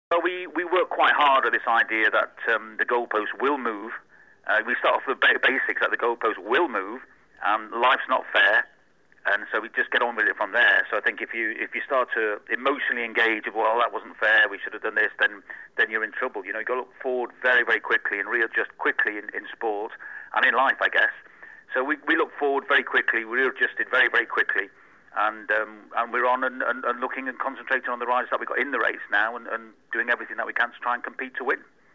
When asked how he felt about the need to change tactics when his main rider crashed out of the race, he answered simply with the tenacity of someone who knows that the path to success is never direct and is always hard work: